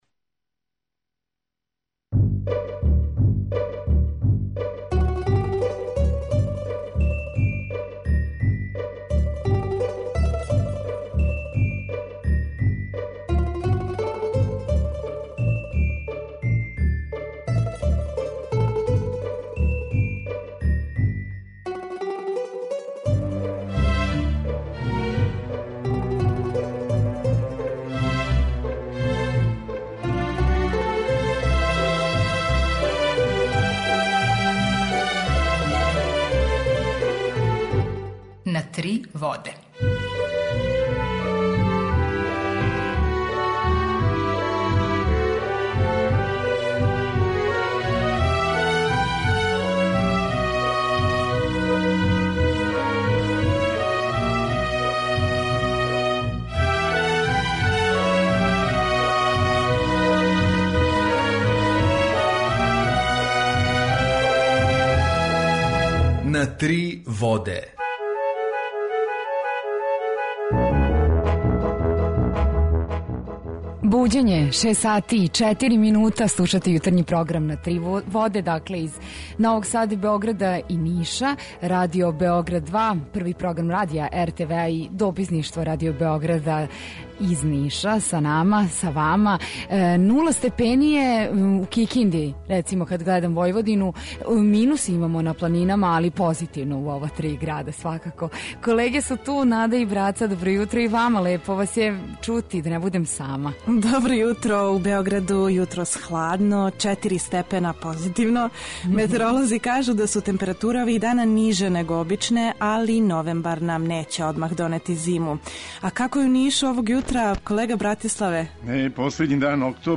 Jутарњи програм заједнички реализују Радио Београд 2, Радио Нови Сад и дописништво Радио Београда из Ниша.
У два сата има и добре музике, другачије у односу на остале радио-станице.